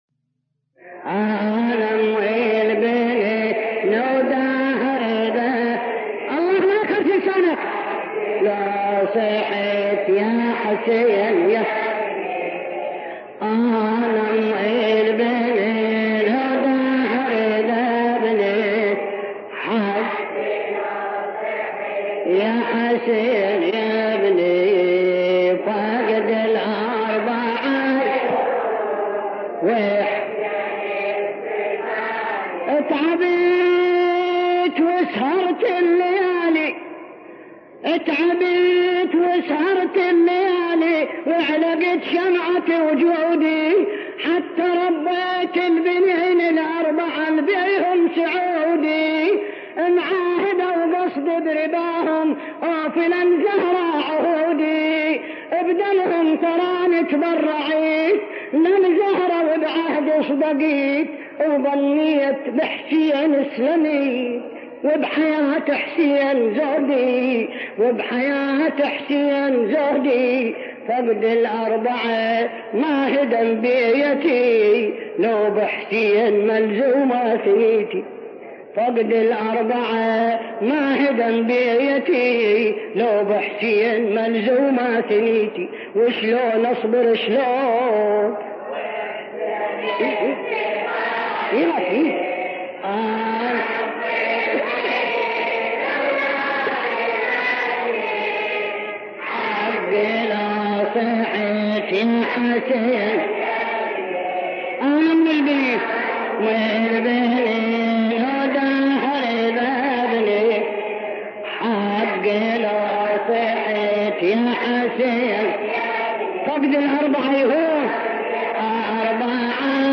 مع قدماء الرواديد